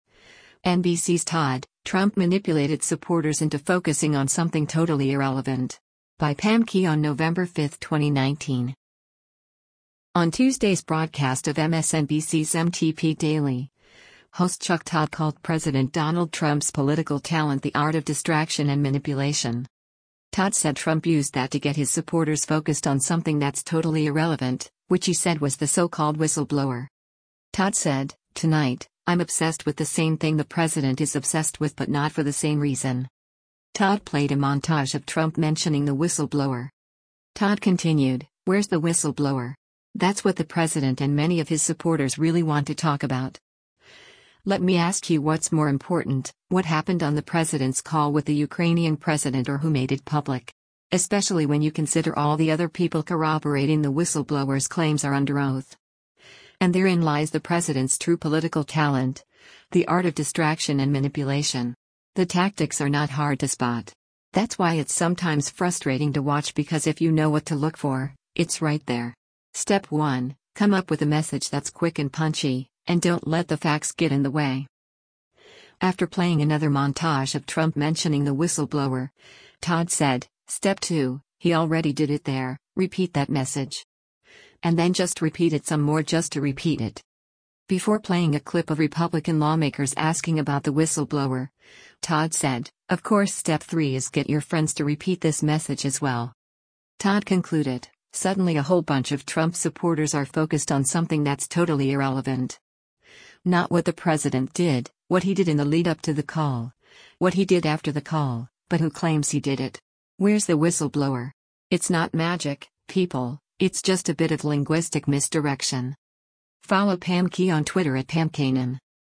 On Tuesday’s broadcast of MSNBC’s “MTP Daily,” host Chuck Todd called President Donald Trump’s political talent “the art of distraction and manipulation.”
Todd played a montage of Trump mentioning the whistle-blower.
Before playing a clip of Republican lawmakers asking about the whistleblower, Todd said, “Of course step three is get your friends to repeat this message as well.”